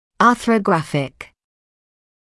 [ˌɑːθrəu’græfɪk][ˌаːсроу’грэфик]артрографический